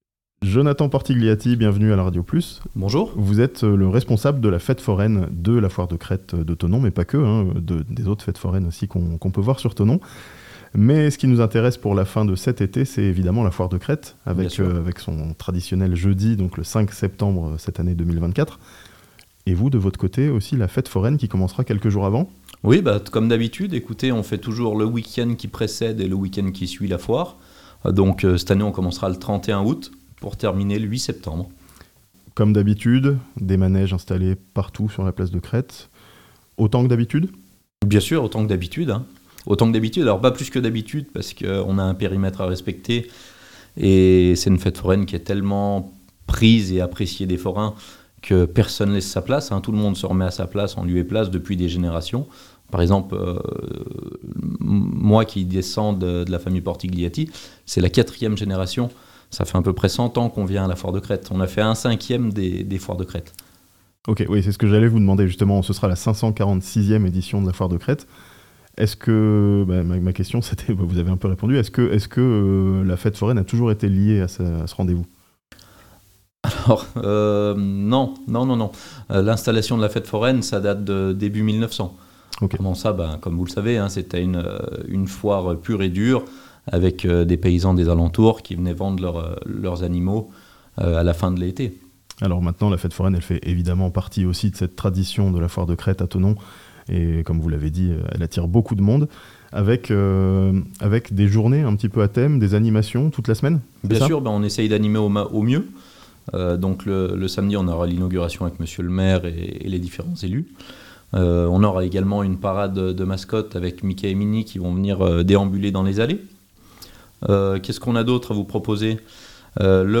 A Thonon, fête foraine et soirées festives accompagneront la foire de Crête (interview)